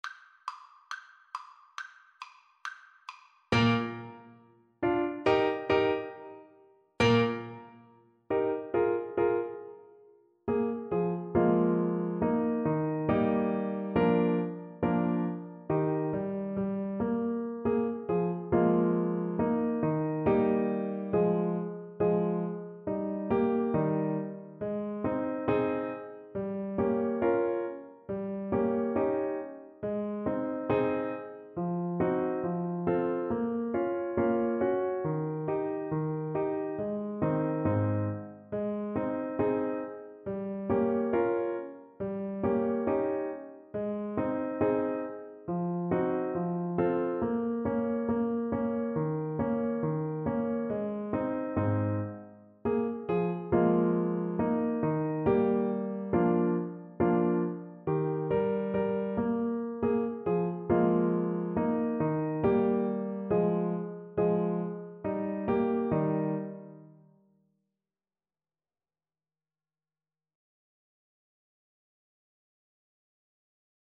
2/4 (View more 2/4 Music)
Classical (View more Classical French Horn Music)
Joyful Music for French Horn
Humorous Music for French Horn